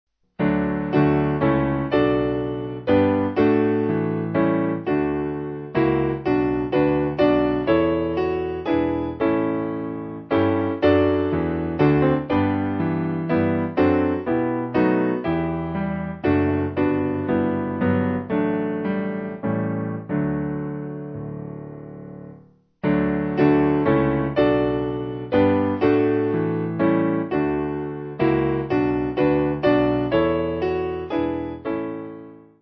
Simple Piano